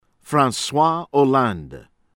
HERNU, CHARLES SHAHRL  AIR-noo